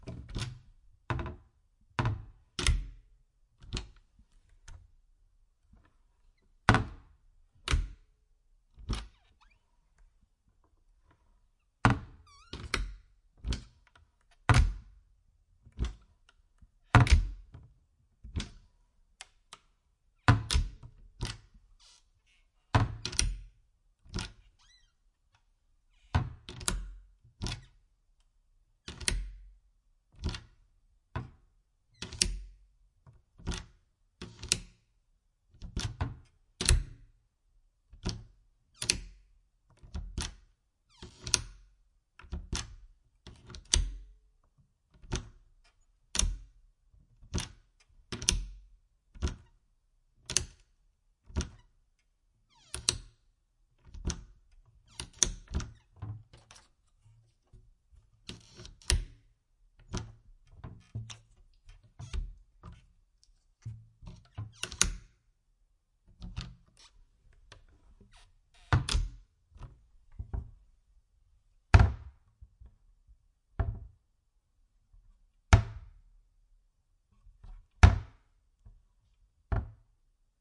随机 "镶嵌玻璃门的木质橱柜开合点击率3
描述：门木柜与镶嵌玻璃打开close clicks3.flac
Tag: 点击 打开 关闭 木材 玻璃 橱柜 镶嵌